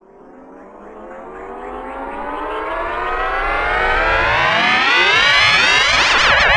Southside Rise Fx.wav